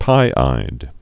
(pīīd)